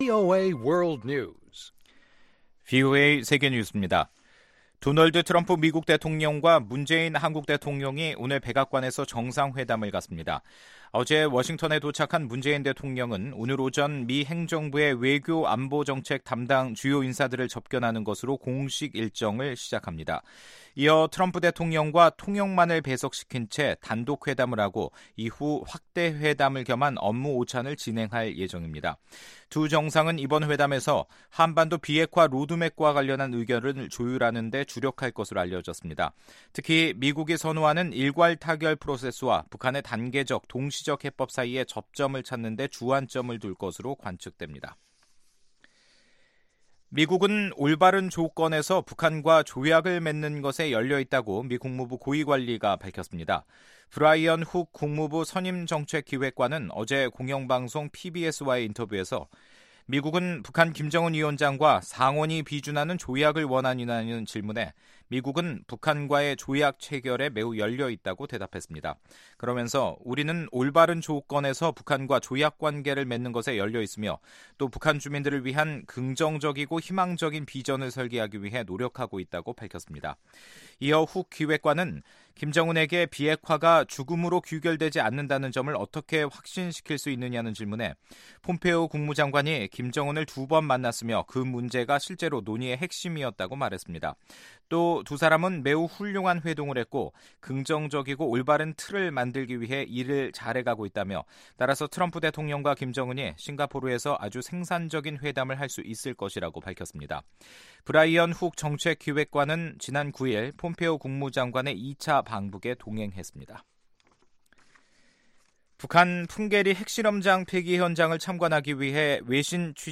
VOA 한국어 간판 뉴스 프로그램 '뉴스 투데이', 2018년 5월 22일 3부 방송입니다. 마이크 펜스 부통령은 김정은 북한 국무위원장이 비핵화를 성사시키기 바란다면서 그렇지 않으면 북한은 리비아 모델 처럼 끝날 수 있다고 말했습니다. 미한 양국 정상이 북한이 수용할 수 있는 비핵화 보상방안을 마련할 수 있을지, 회담의 핵심의제에 대해 '뉴스해설'에서 자세하게 살펴보겠습니다.